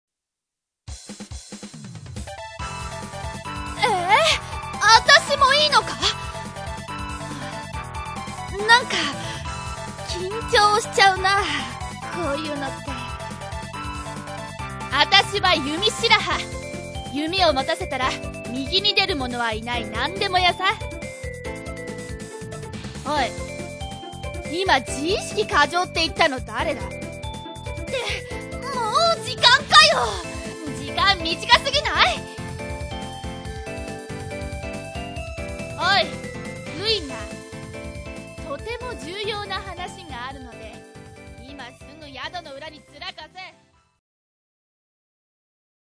自己紹介ボイス"